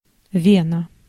Ääntäminen
France: IPA: /vɛn/